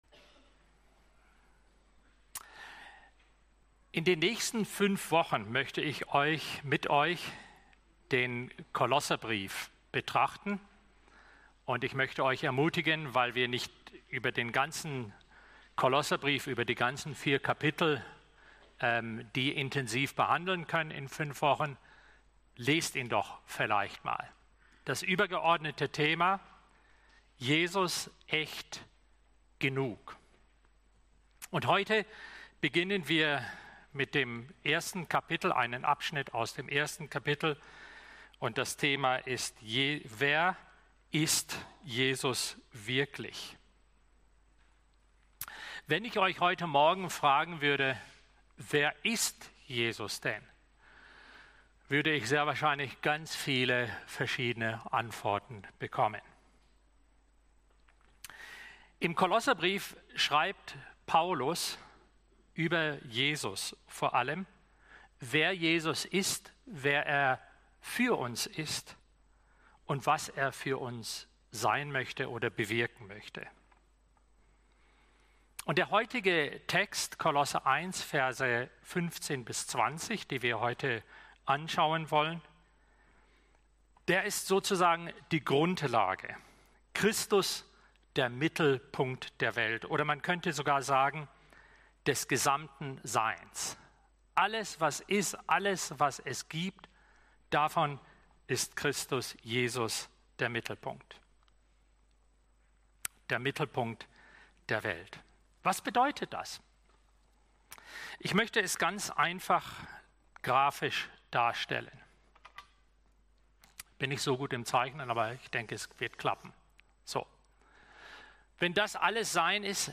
Gottesdienst_-Wer-ist-Jesus-wirklich_-Der-Mittelpunkt-der-Welt-Kolosser-1-15-20.mp3